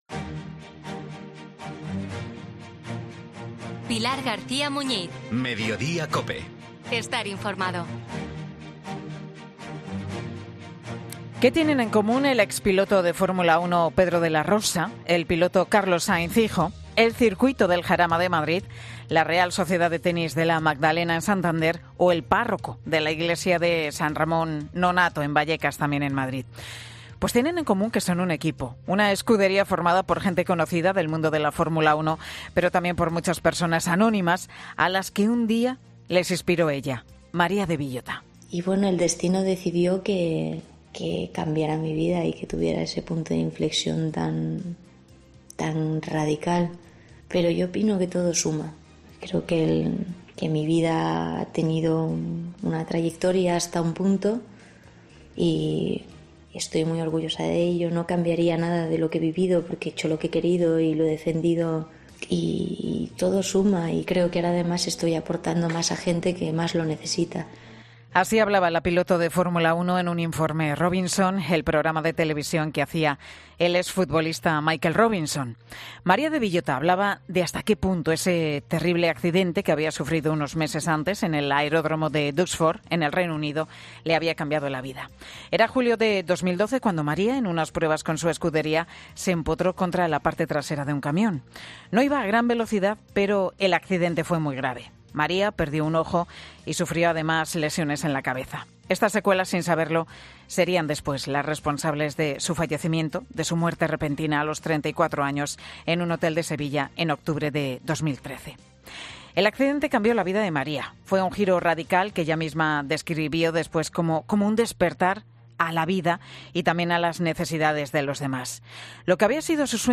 Emilio de Villota recuerda el "legado" de su hija María en Mediodía COPE
Desde el circuito del Jarama, un lugar muy especial para toda la familia Villota, Emilio recuerda que el movimiento Legado de María de Villota lleva a cabo cuatro grandes proyectos.